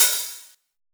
909 OHH.wav